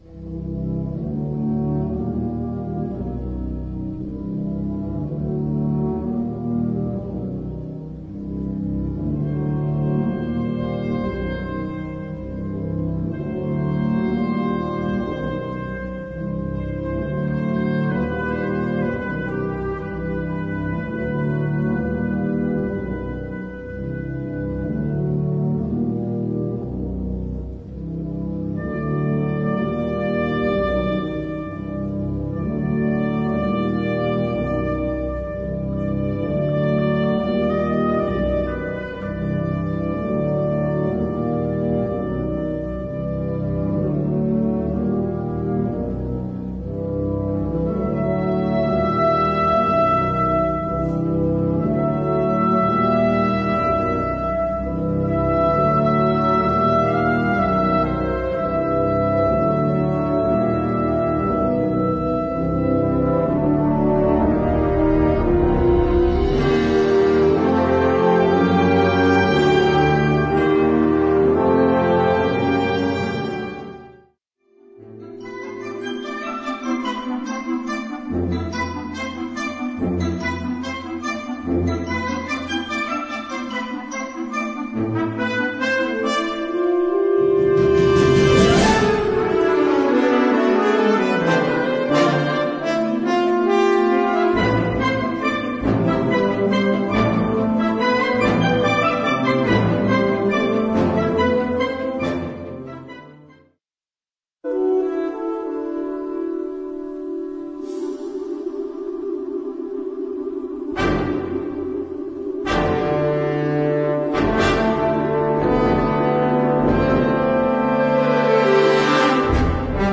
Catégorie Harmonie/Fanfare/Brass-band
Sous-catégorie Musique à vent contemporaine (1945-présent)
Instrumentation Ha (orchestre d'harmonie)